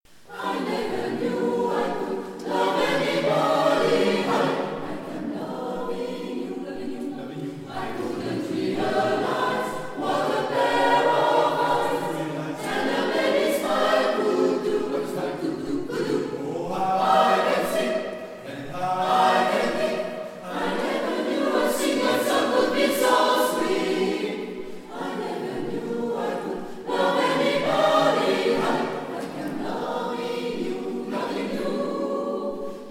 Ensemble vocal Mezzoferté